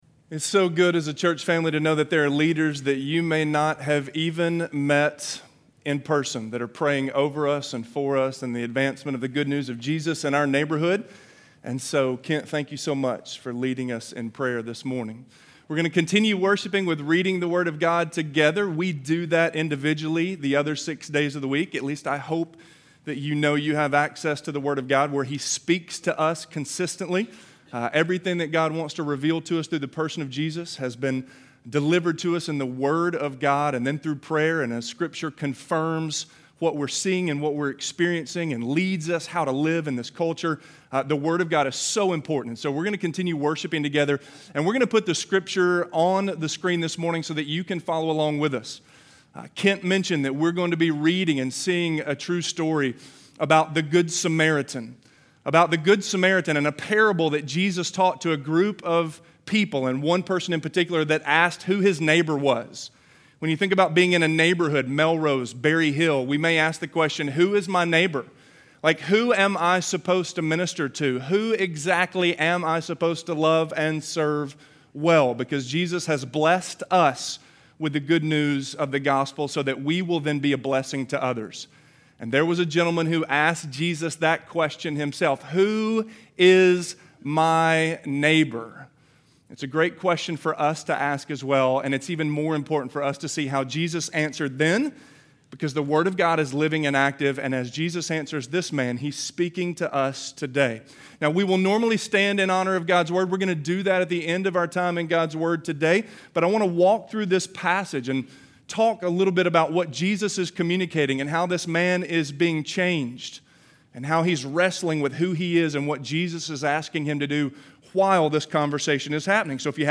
The Gospel Compels Us to Love - Sermon - Avenue South